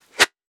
weapon_bullet_flyby_02.wav